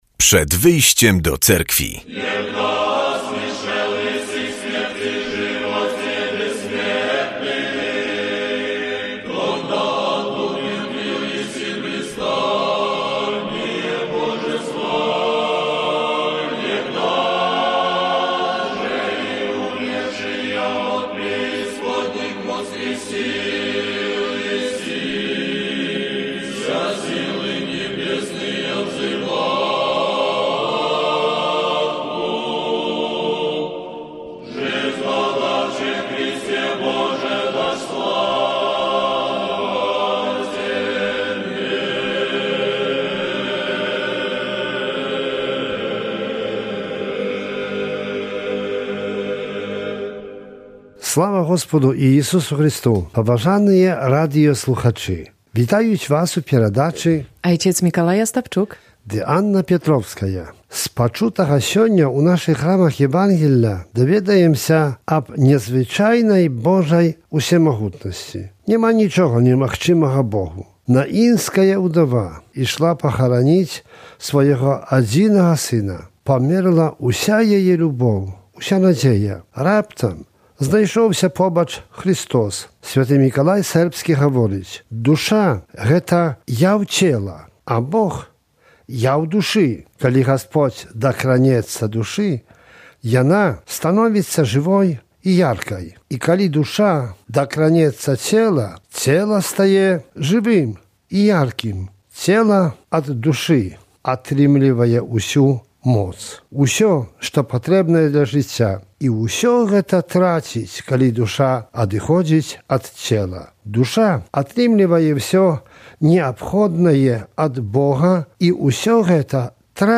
W audycji usłyszymy kazanie na temat dzisiejszej Ewangelii i informacje z życia Cerkwi prawosławnej.